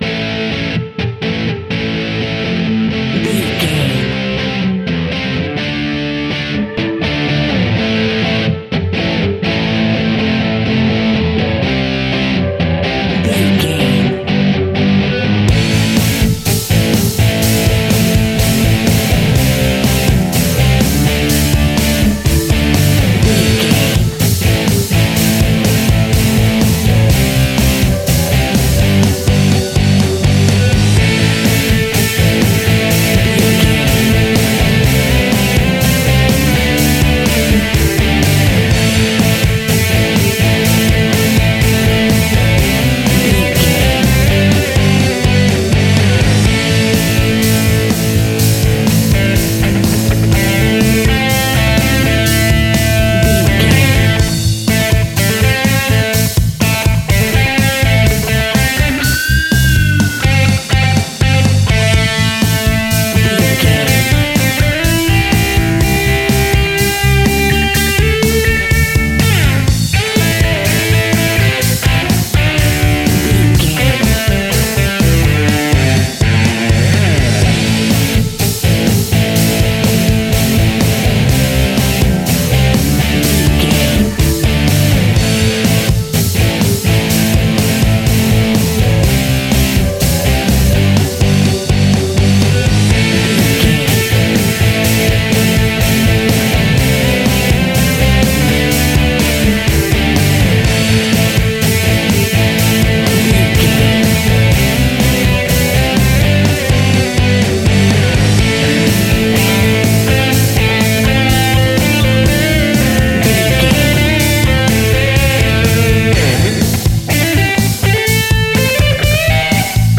Epic / Action
Fast paced
Aeolian/Minor
hard rock
heavy rock
blues rock
distortion
instrumentals
Rock Bass
heavy drums
distorted guitars
hammond organ